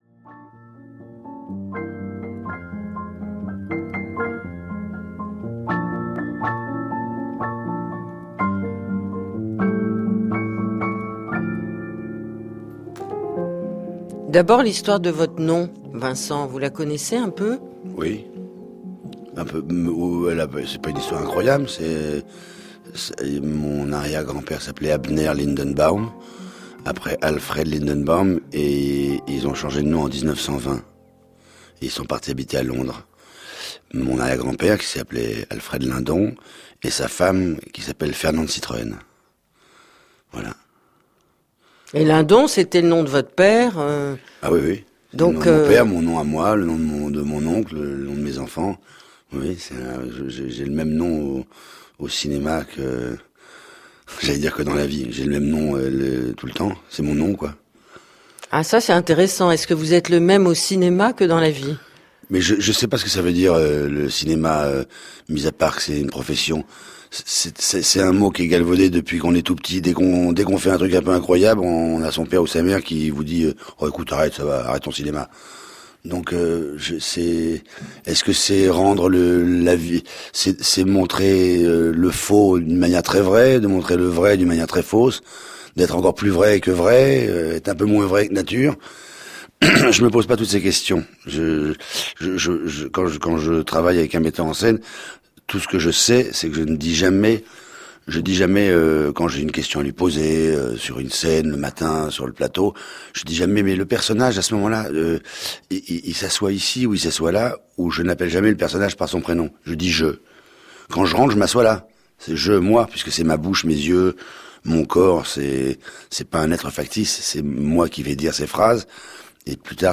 Pour introduire le sujet, la rédaction propose des extraits d’interview.
1ère écoutes, extraits d’interview
- Laure Adler interview Vincent Lindon dans l’émission Hors-champs sur France Culture.